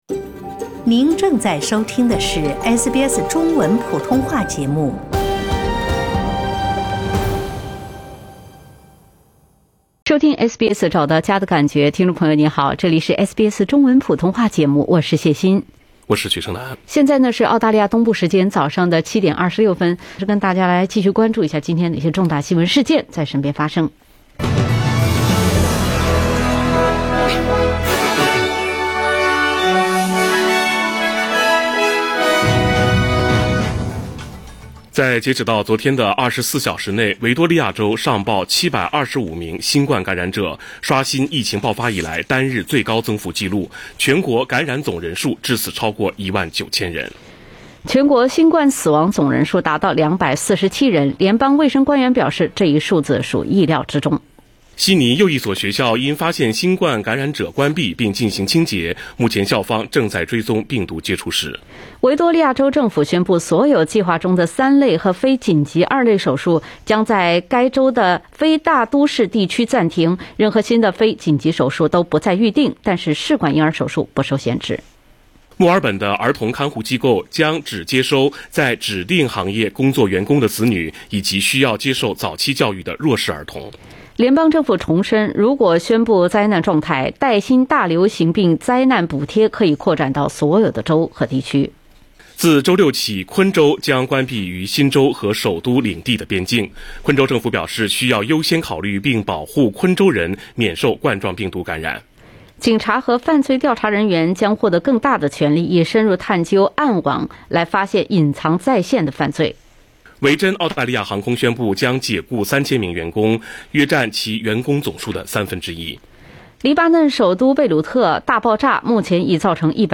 SBS早新闻（8月6日）